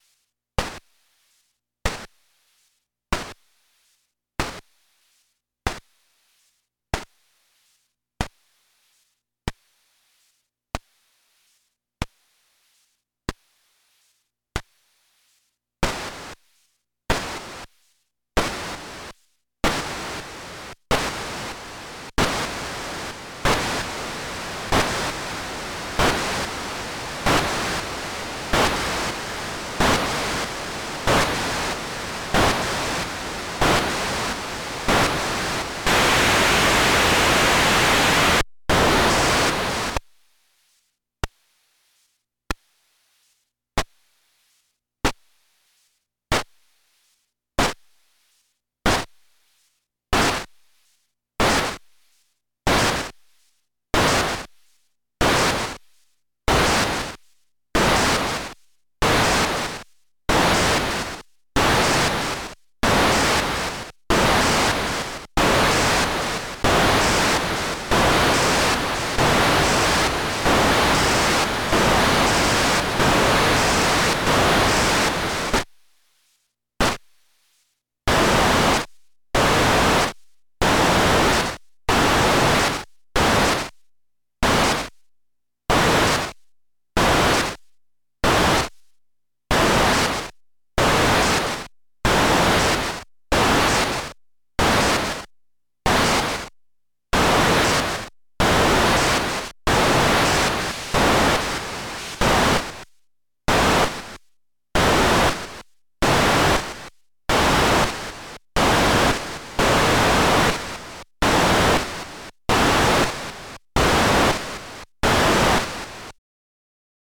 This circuit creates two percussive noise voices, it acts as noise source, VCA and envelope generator all in one.
1.Dual Decaying Noise - 2 digital noise outputs, the knobs set the decay, and the switches select between linear and logarithmic decay curves and whether decay begins at the rising or falling edge of the input trigger/gate.
This MP3 demonstrates the sound of the Dual Decaying Noise software. At the start of the demo, it's in logarithmic/closed mode, then I do linear/closed, then linear/open, then log/open.